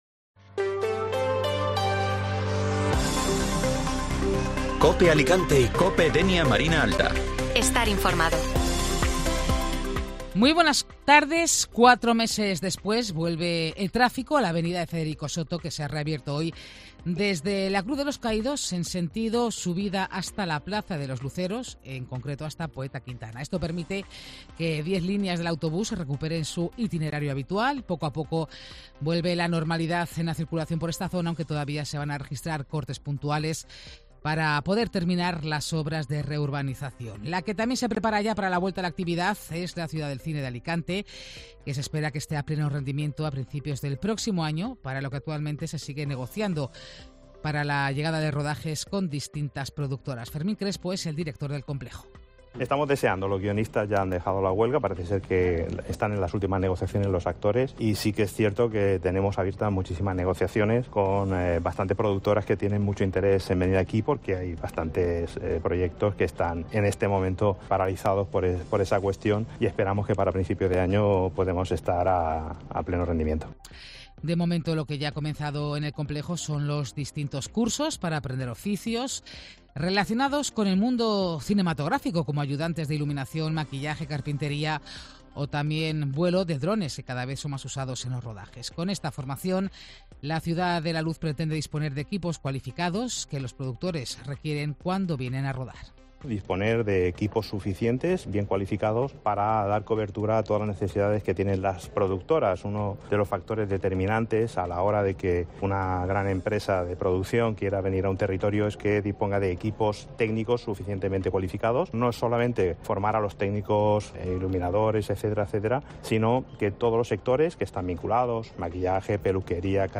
Informativo Mediodía Cope Alicante (Lunes 16 de Octubre)